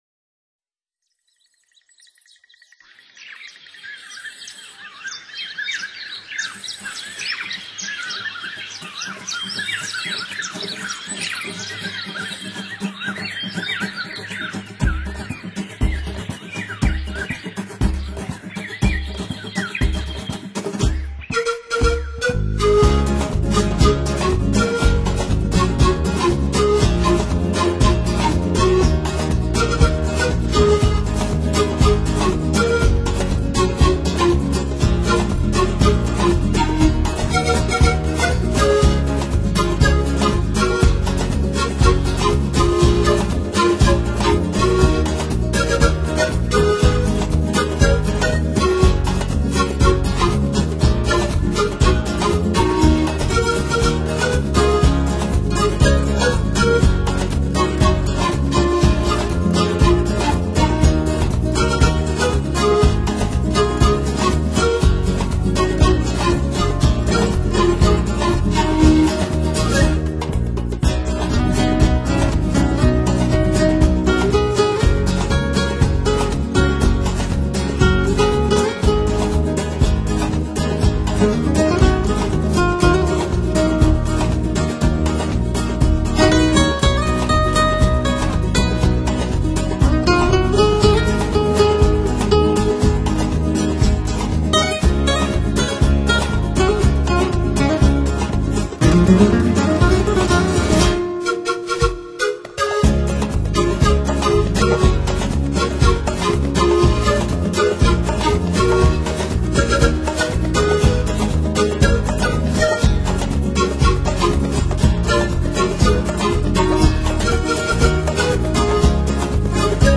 音乐类别：NEWAGE